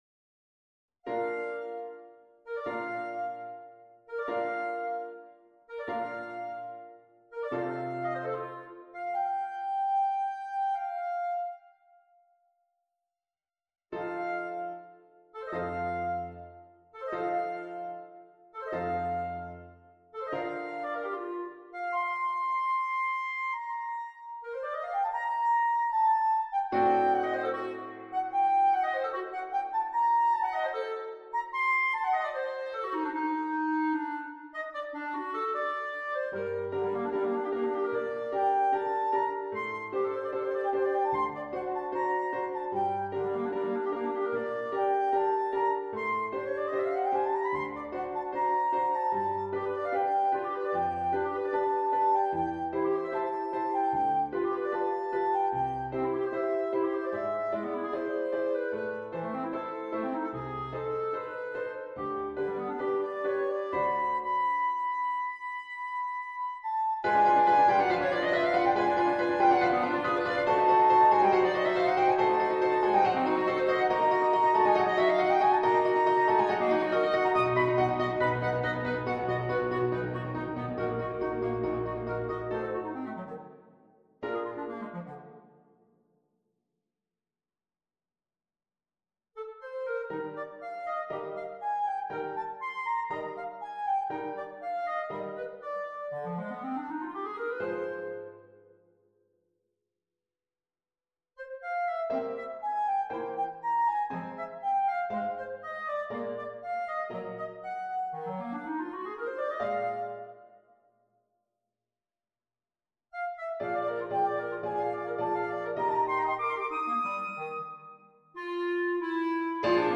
Per clarinetto e pianoforte